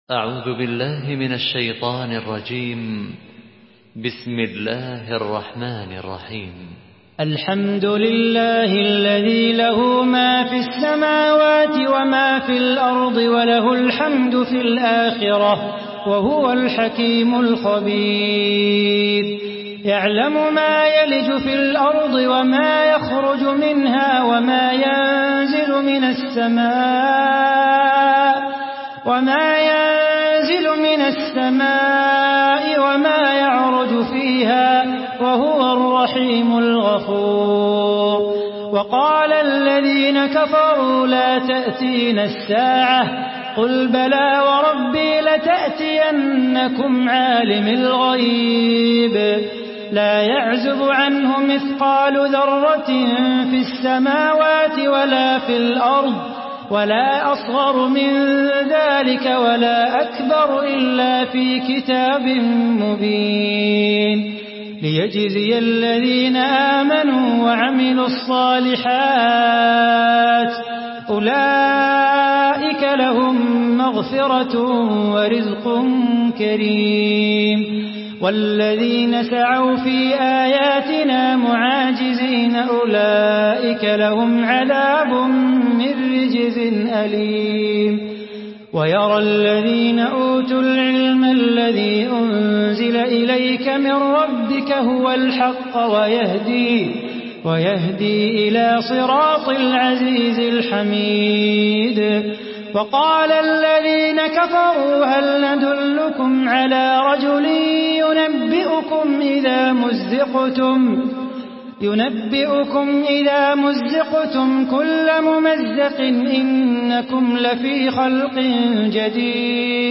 Surah সাবা MP3 by Salah Bukhatir in Hafs An Asim narration.
Murattal